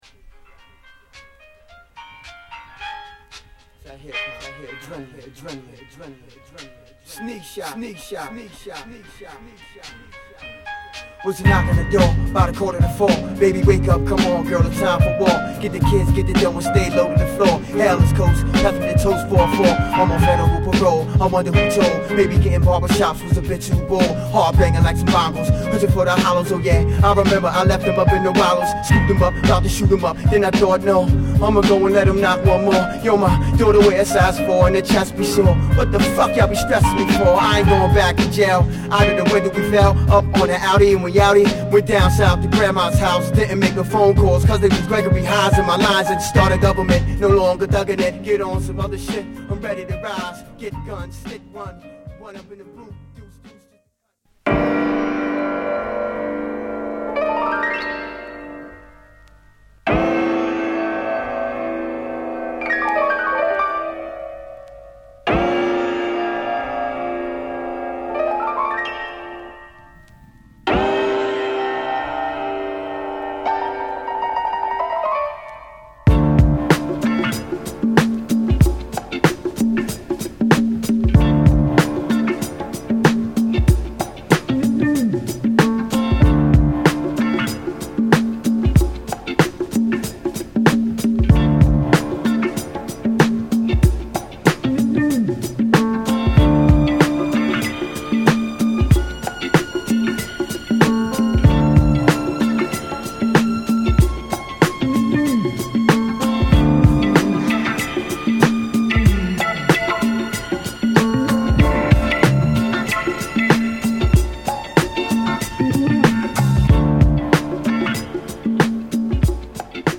(Freestyle
(Instrumental)